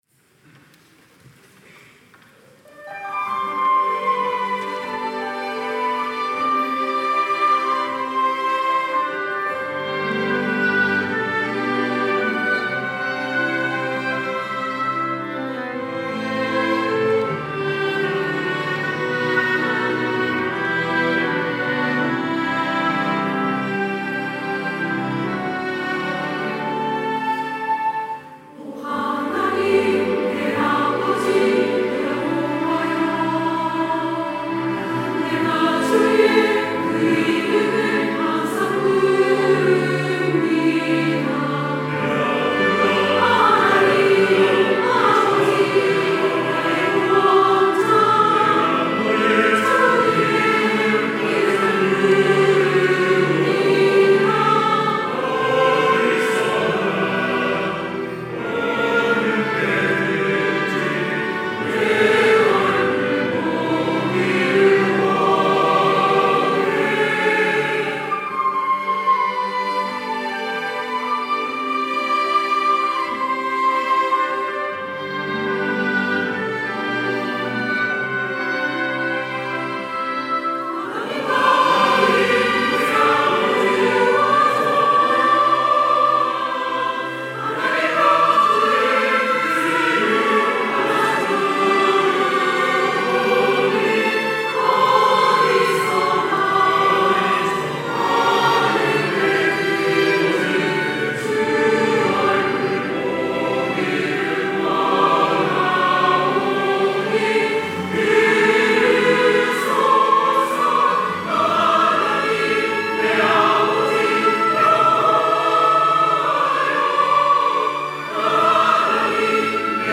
호산나(주일3부) - 오 하나님
찬양대